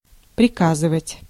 Ääntäminen
Tuntematon aksentti: IPA: /prʲɪˈkazɨvətʲ/